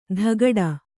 ♪ dhagaḍa